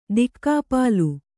♪ dikkāpālu